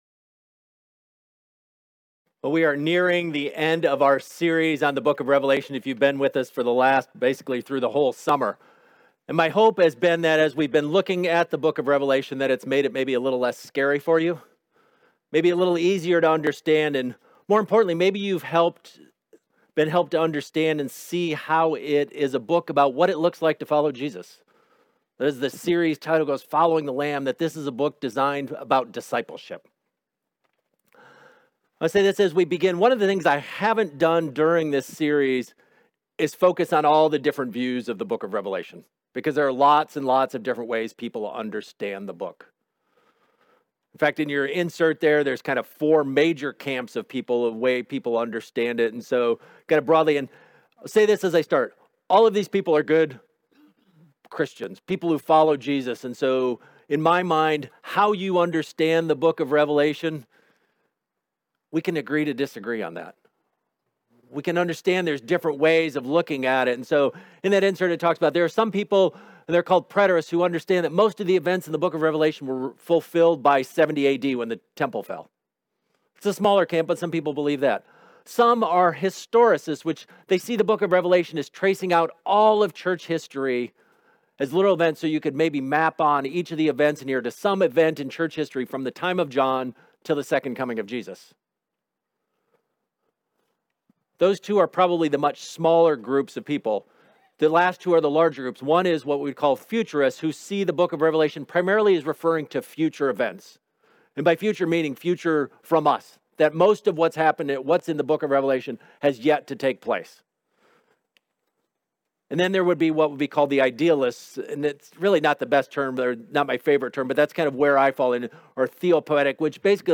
Sermons | Fruitland Covenant Church